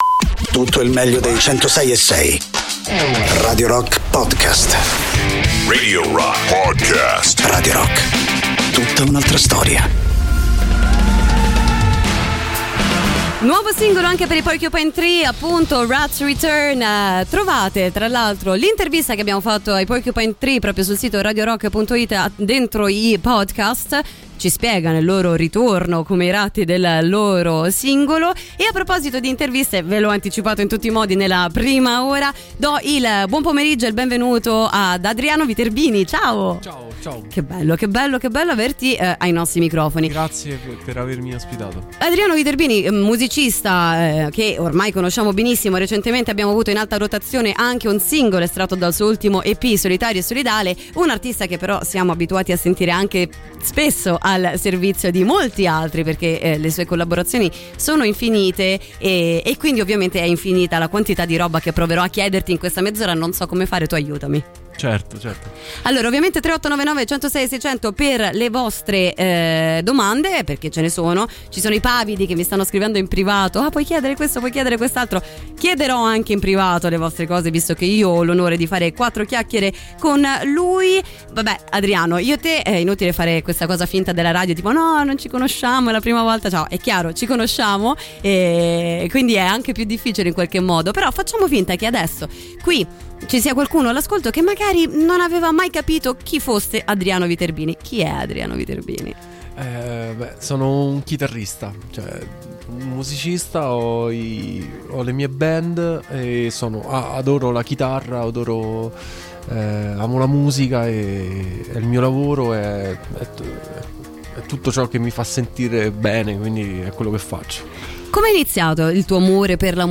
Intervista
ospite in studio